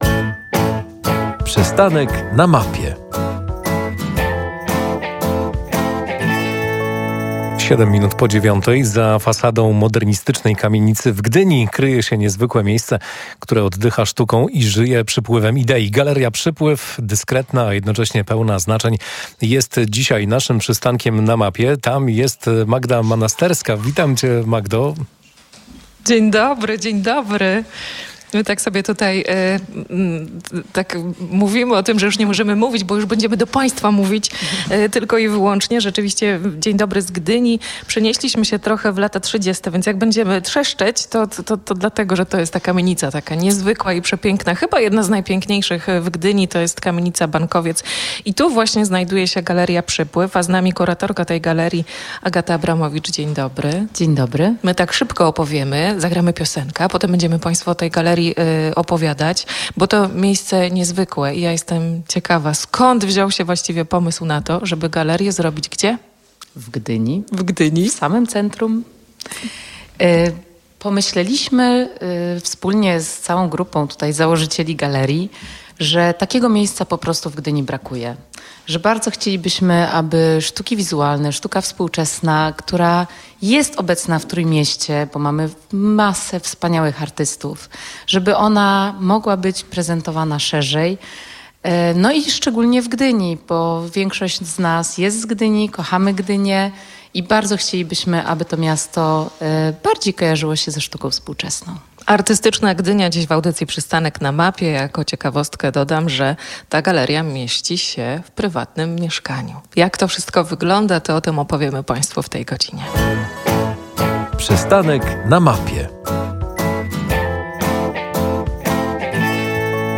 Radio Gdańsk nadawało audycję „Przystanek na mapie” z Galerii Przypływ w Gdyni.